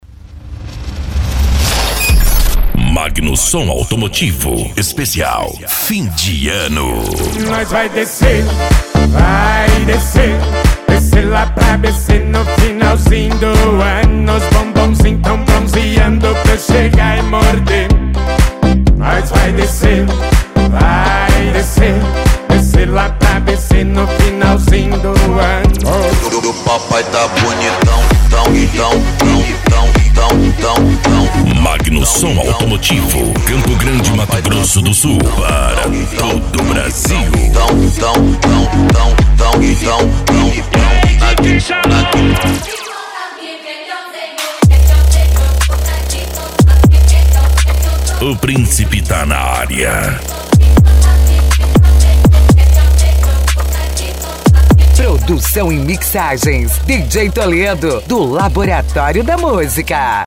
Funk
Sertanejo Universitario